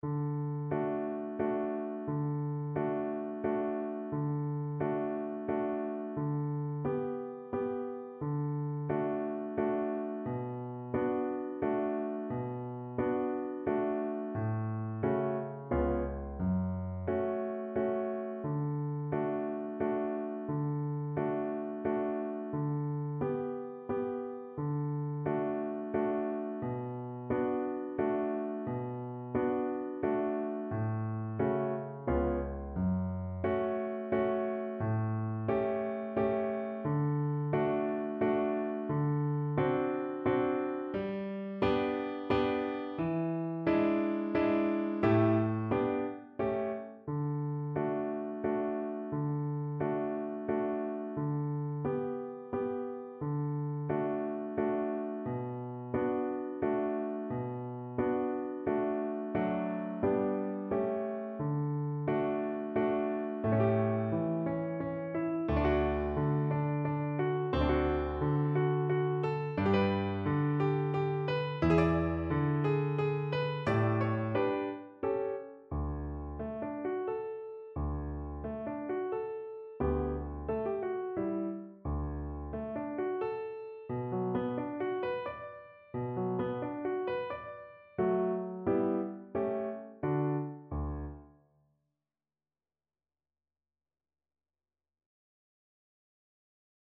Symulacja akompaniamentu
brahms_walc_nuty_vc-pf-acc..mp3